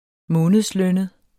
Udtale [ -ˌlœnəð ]